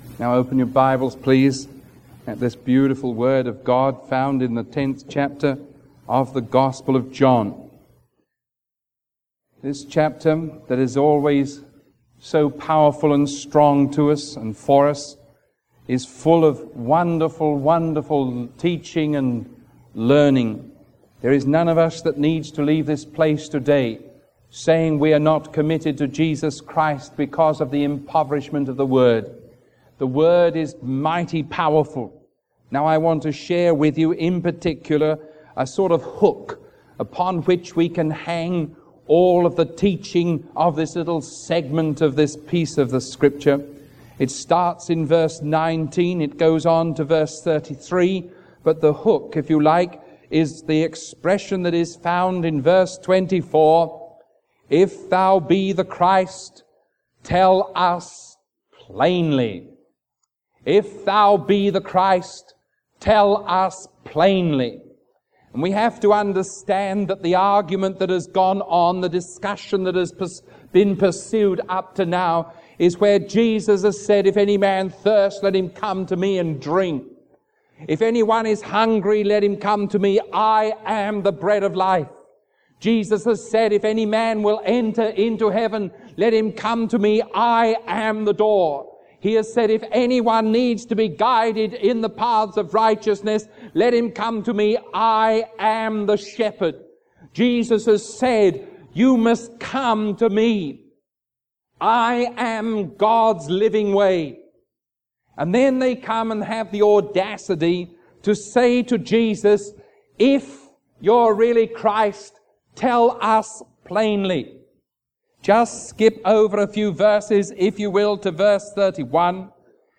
Sermon 0386A recorded on July 19